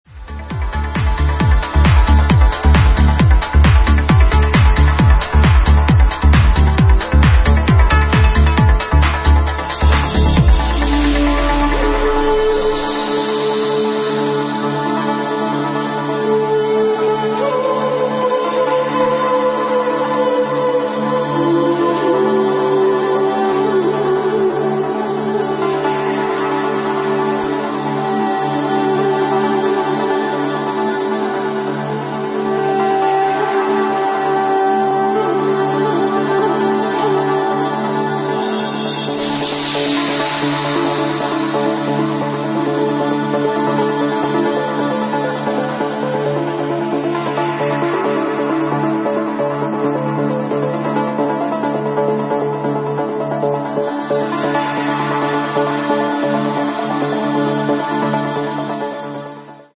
lush chilled track